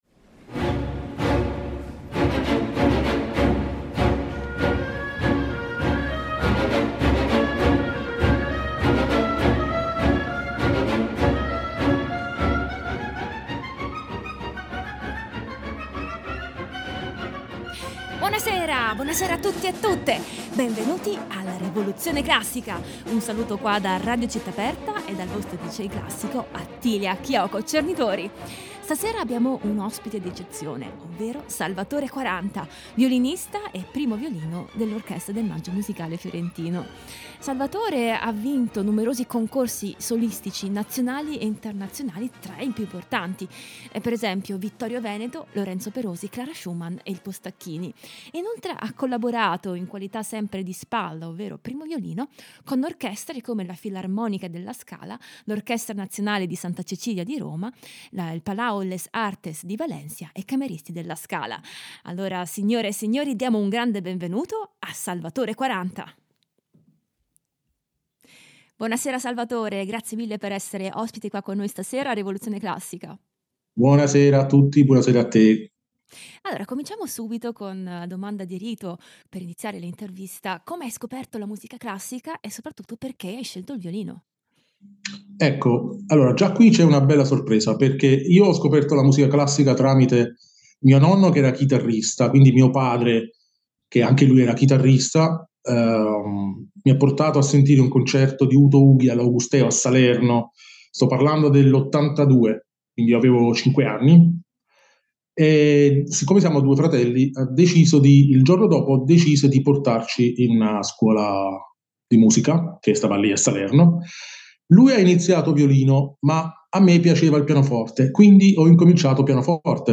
Ospite di questa puntata il violinista
violino
pianoforte
per due violini e archi
per violino e orchestra